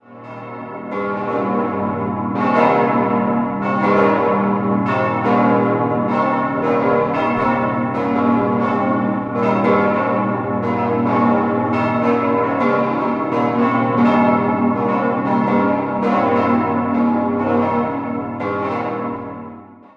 Idealquartett: gis°-h°-cis'-e' Alle Glocken wurden 1951 vom Bochumer Verein für Gussstahlfabrikation in V7e-Rippe gegossen und haben ein Gesamtgewicht von ca. 12,5 Tonnen.